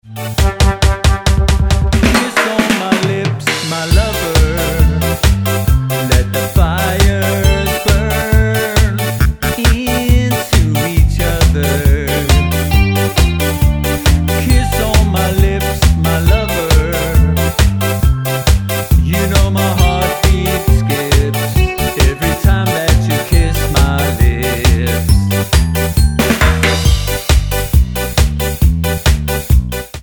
Tonart:G mit Chor
Die besten Playbacks Instrumentals und Karaoke Versionen .